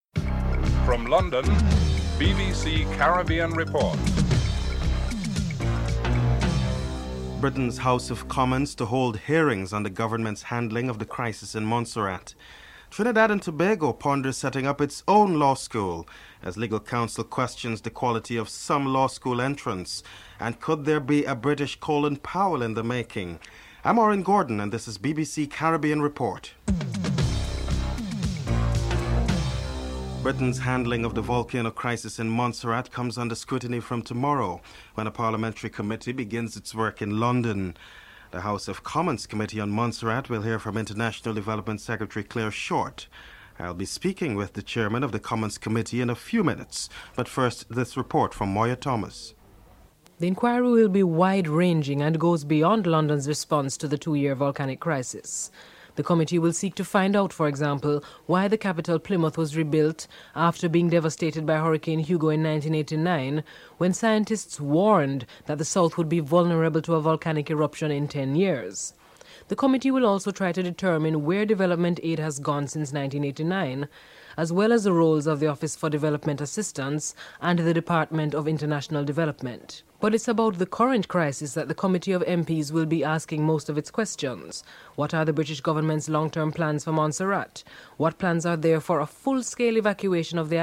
1. Headlines (00:00-00:29)
Members of the Armed Forces are interviewed
6. While in Venezuela President Bill Clinton restated America's aim for a free trade area of the Americas within ten years. President Bill Clinton is interviewed (14:33-15:25)